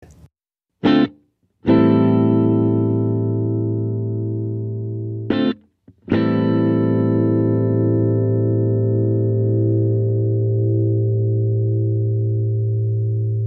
Sobre el audio: sin el compresor: 0:00-0:04/con el compresor: 0:05-0:13